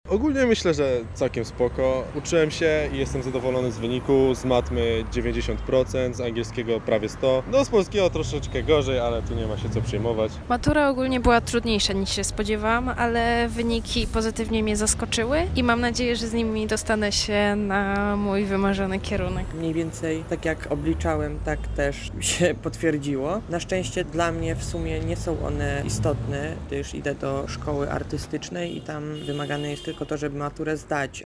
Zapytaliśmy lubelskich maturzystów, jak oceniają swoje wyniki:
sonda